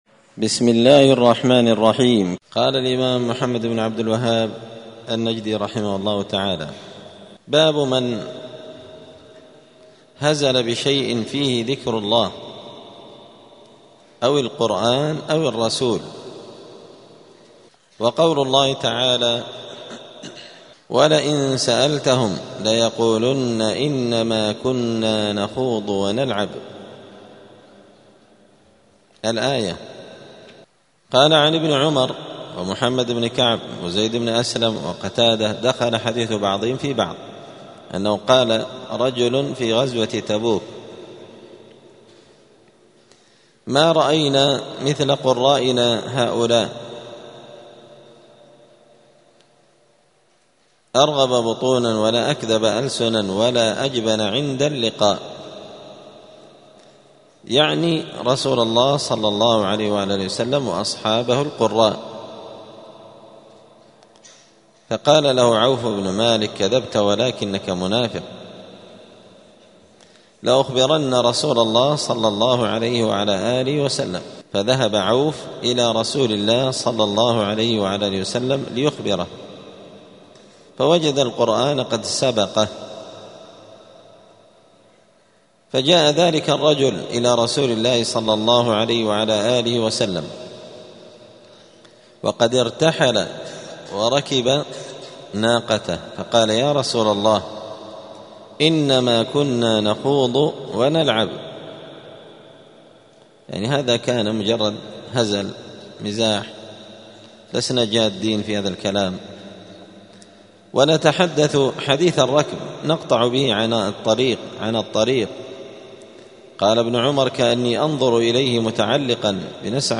دار الحديث السلفية بمسجد الفرقان قشن المهرة اليمن
*الدرس السابع والعشرون بعد المائة (127) {باب من هزل بشيء فيه ذكر الله أو الرسول}*